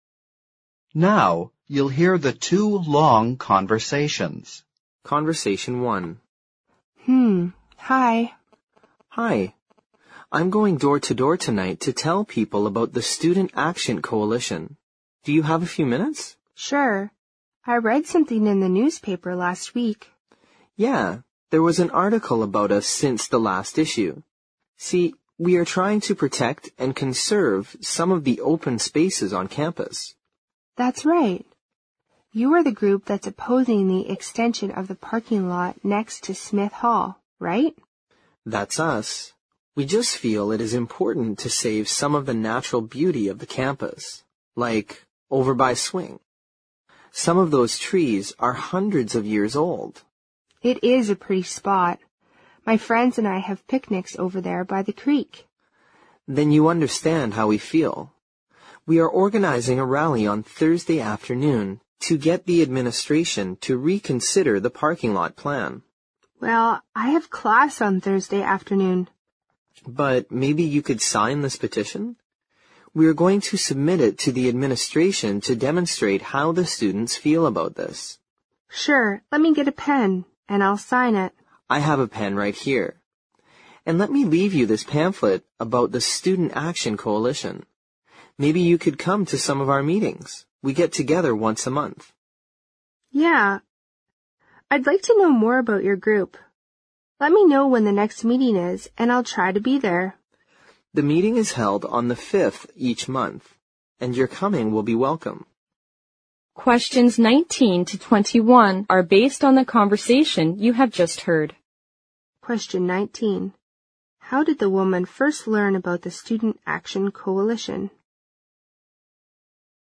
Conversation One